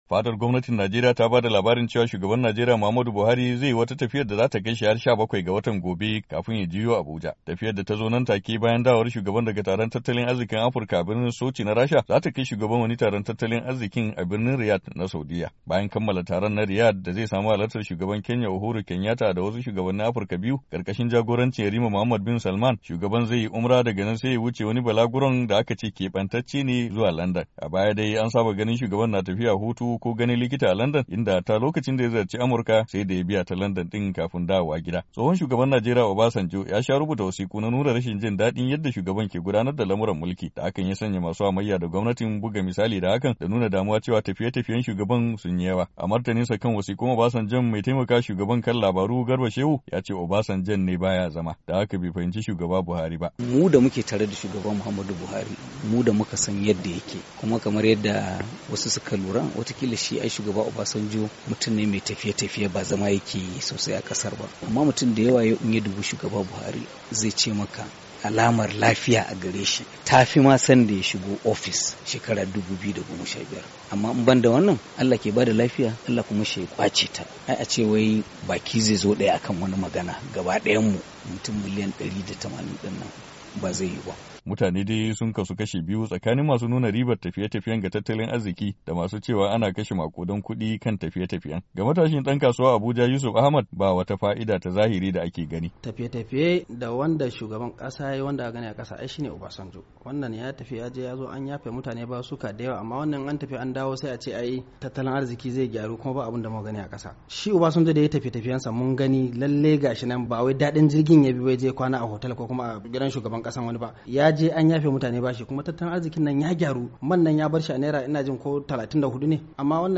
Saurari rahoton wakilinmu